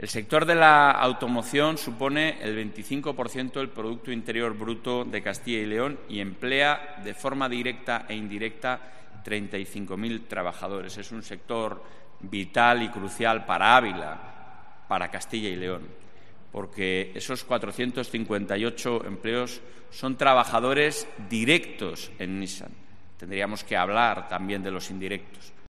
En la inauguración, el presidente de la Junta CyL, Alfonso Fernández Mañueco, ha asegurado que la reconversión de Nissan ha sido “impresionante” con un solo año para levantar este nuevo edificio.